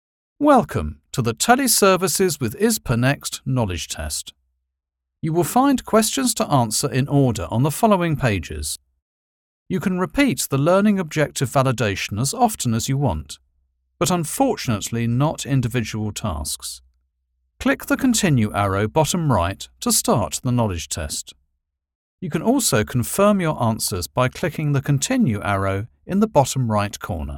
English speaker, BBC, RP, educated, smooth, clear, sophisticated, stylish, precise
Sprechprobe: eLearning (Muttersprache):
I have a stylish, precise English voice, educated and sophisticated.
e-learning – car company.mp3